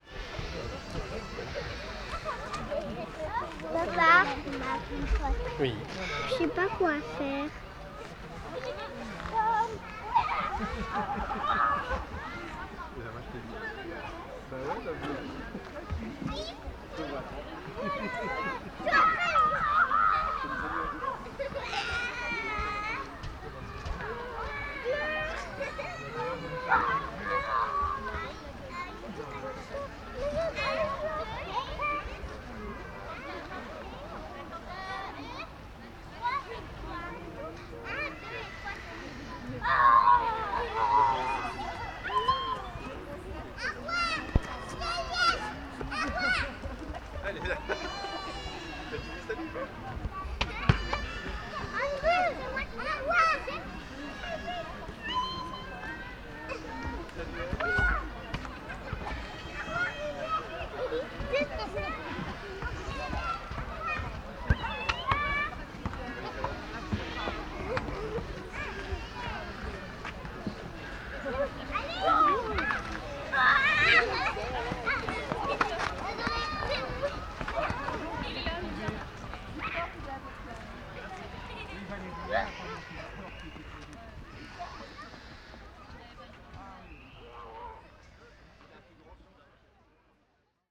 Strasbourg, square des moulins - Nagra Lino